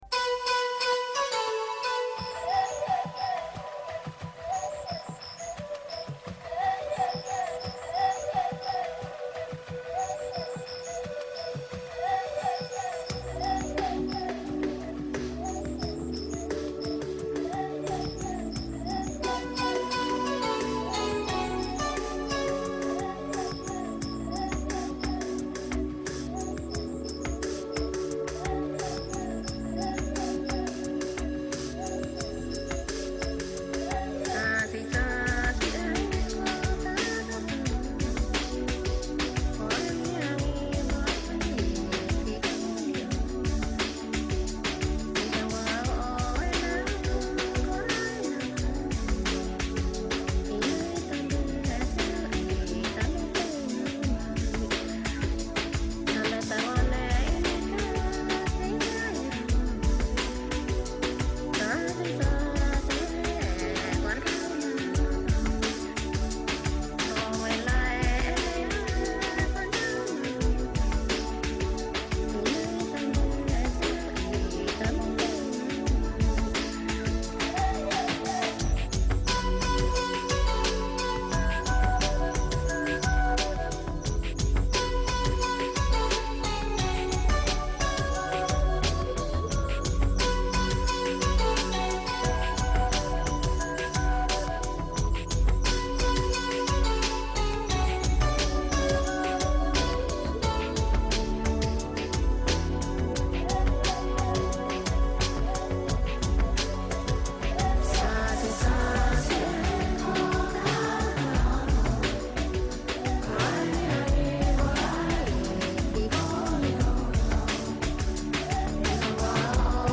Στην εκπομπή Doc On Air μας μιλούν: